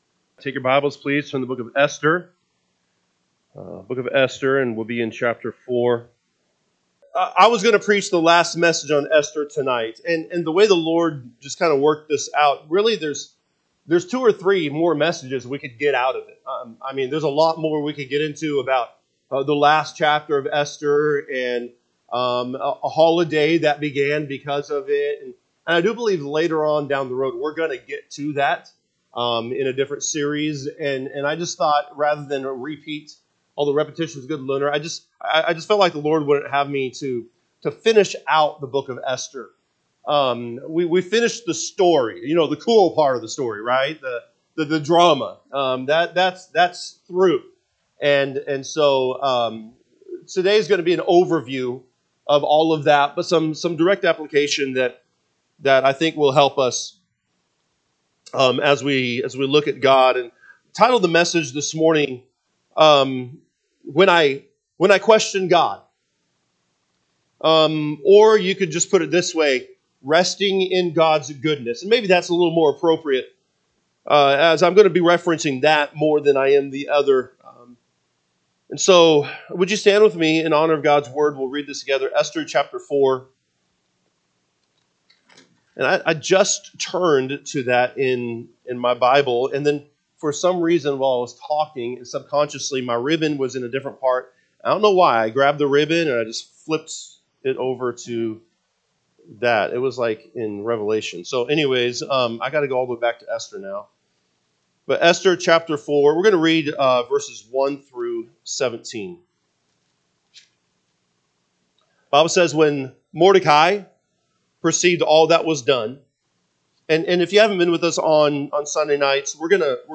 June 29, 2025 am Service Esther 4 (KJB) 4 When Mordecai perceived all that was done, Mordecai rent his clothes, and put on sackcloth with ashes, and went out into the midst of the city, and cr…